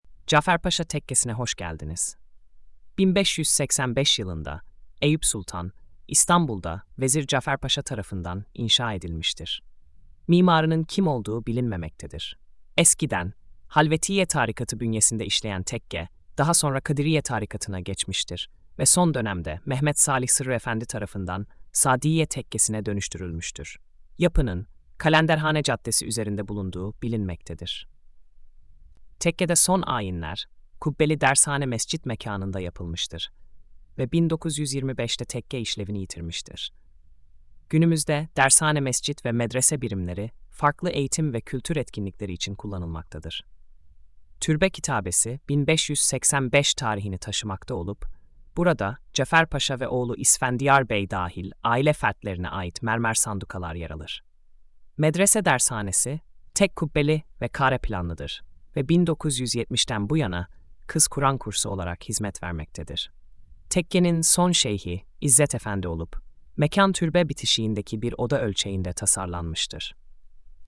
Sesli Anlatım: